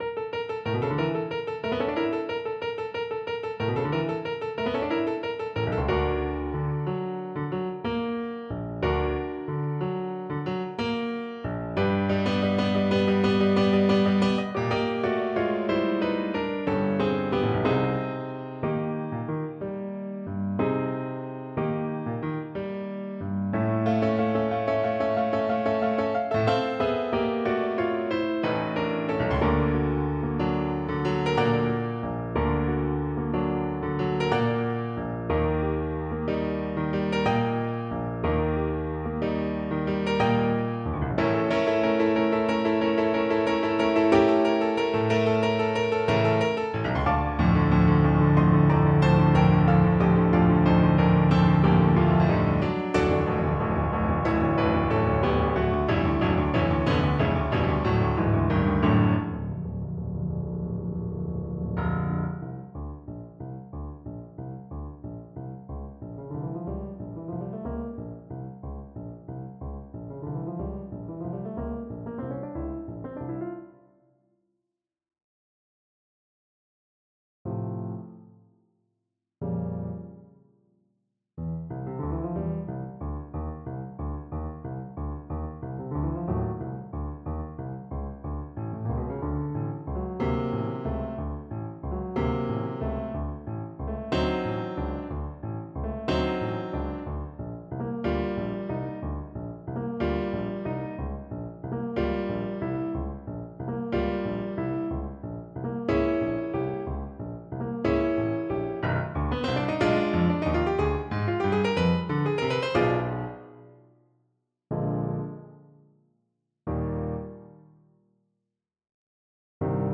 Classical Wagner, Richard Ho jo to ho! from Die Walküre Trombone version
Trombone
D minor (Sounding Pitch) (View more D minor Music for Trombone )
Allegro . = 90 (View more music marked Allegro)
9/8 (View more 9/8 Music)
Classical (View more Classical Trombone Music)